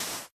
sand1.ogg